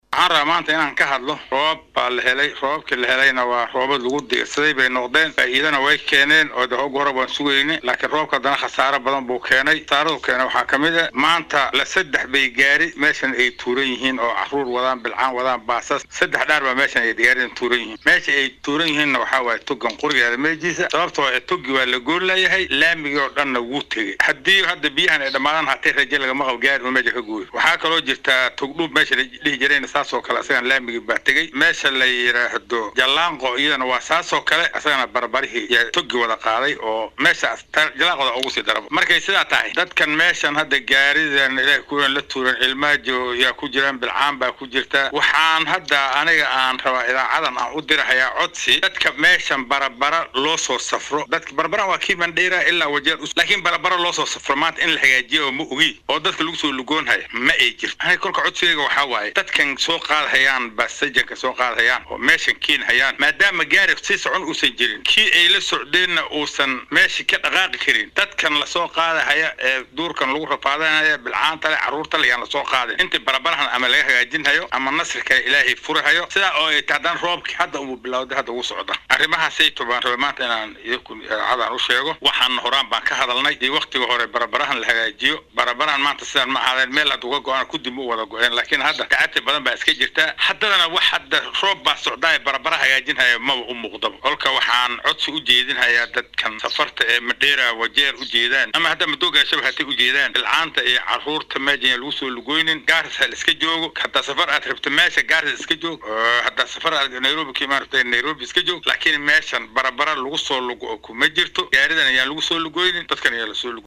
Guddoomiyaha nabadgalyada ee deegaanka Lagdera ee ismaamulka Garissa Dhaqane Faliir ayaa ka hadlay saameynta roobabka da’ay ay ku yeesheen waddooyinka. Waxaa uu sheegay in aan la mari karin waddada u dhexeysa Garissa iyo Madogashe sidaasi awgeedna uu gaadiidleyda rabaabka qaadaya baaq u diraya.